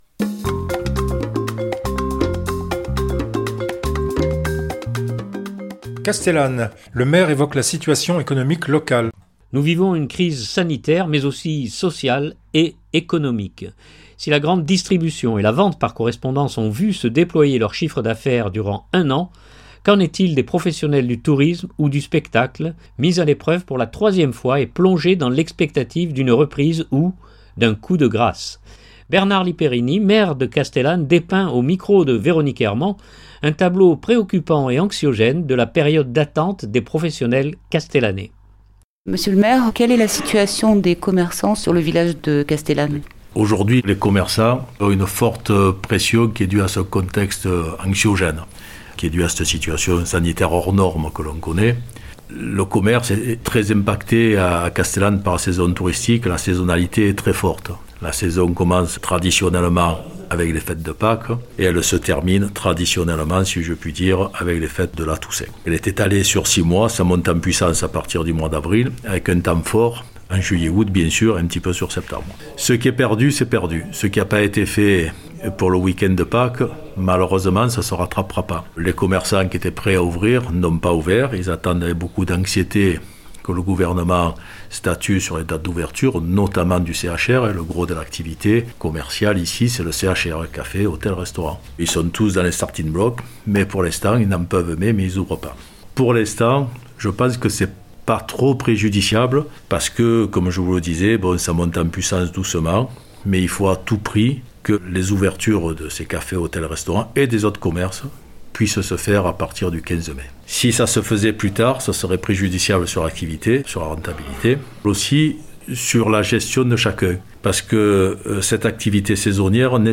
Si la grande distribution et la vente par correspondance ont vu se déployer leur chiffre d’affaire durant un an, qu’en est-il des professionnels du tourisme ou du spectacle, mis à l’épreuve pour la 3ème fois et plongés dans l’expectative d’une reprise ou… d’un coup de grâce ? Bernard Lipérini, Maire de Castellane, dépeint